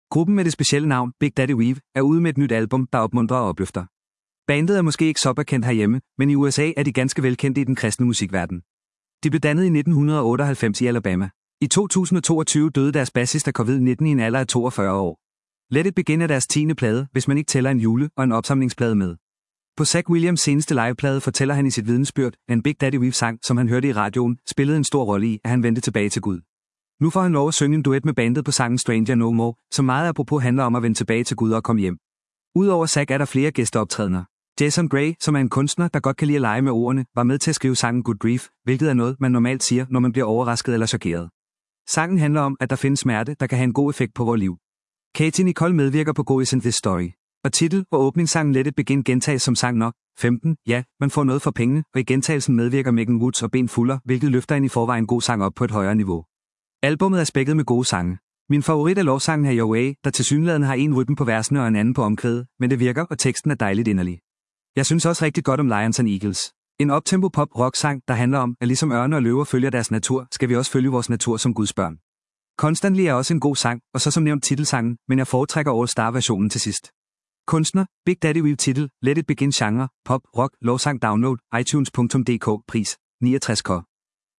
Genre: Pop/Rock/Lovsang